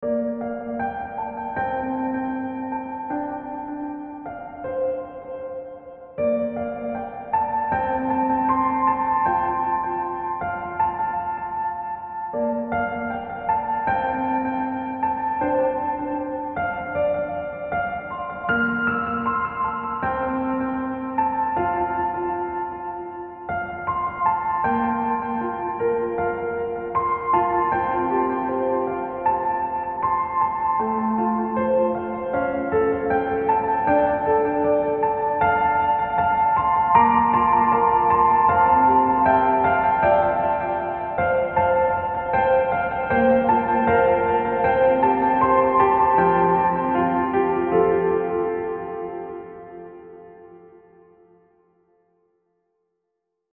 未分類 どこか懐かしい夏 ピアノ 幻想的 懐かしい 穏やか 青春 音楽日記 よかったらシェアしてね！